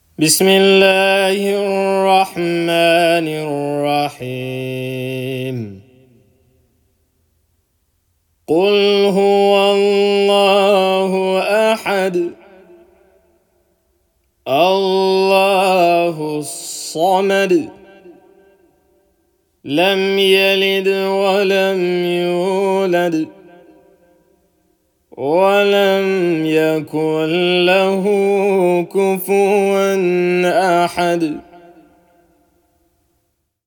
수라 알-이클라스의 무자와드 꾸란 낭송.